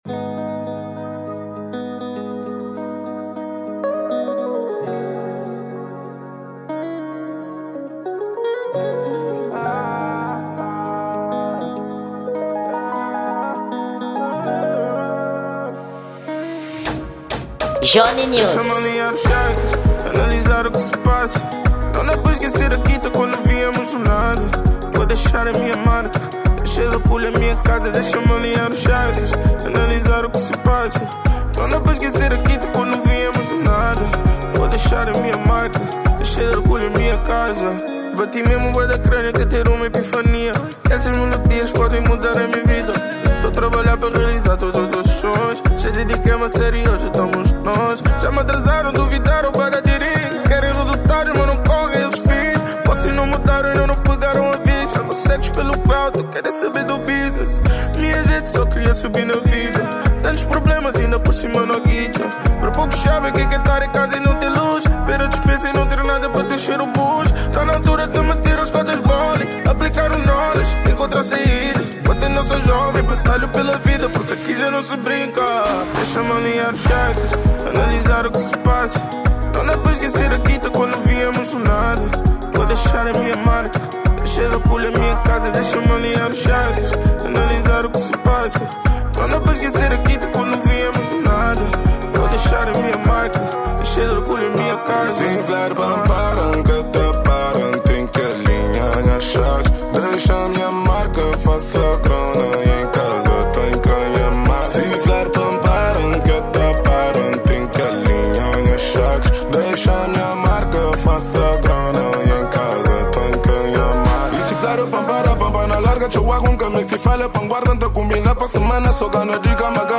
Gênero: Afro Beat